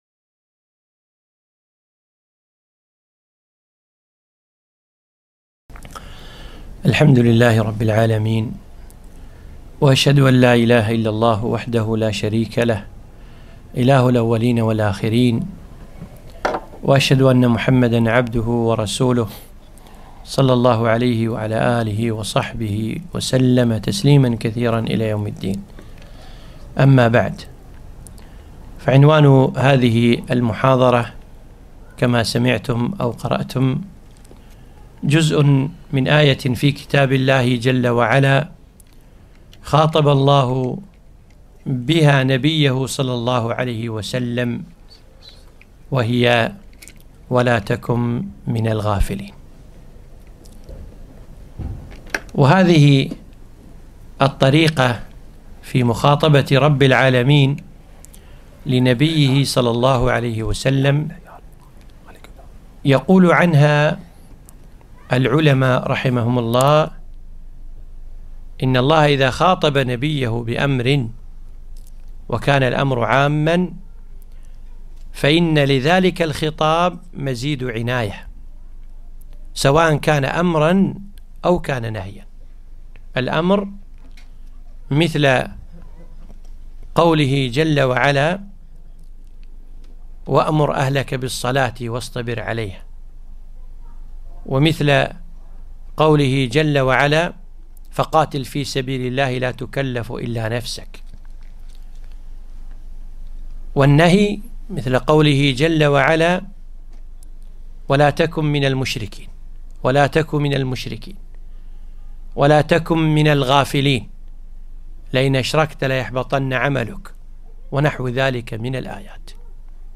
محاضرة - ( وَلا تَكُن مِنَ الغافِلينَ )